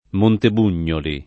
[ monteb 2 n’n’oli ]